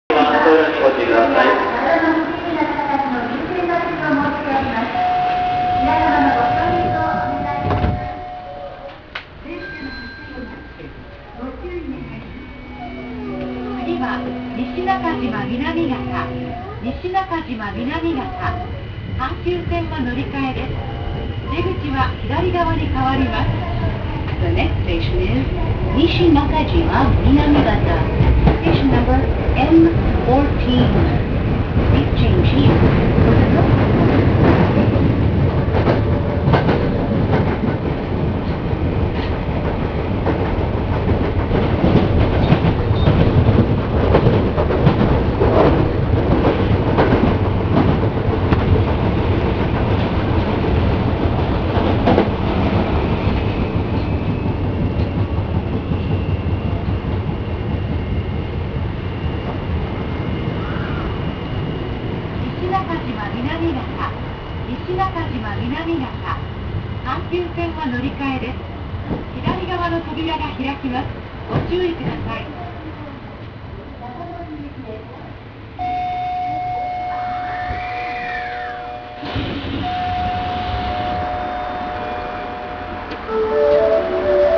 ・9000形走行音
【御堂筋線】新大阪→西中島南方（1分24秒：460KB）
ここ数年、関東の私鉄で採用例が増えている東芝PMSM（永久磁石同期電動機）の主電動機を採用しています。東京メトロで採用されているものとほぼ同じ音となっています。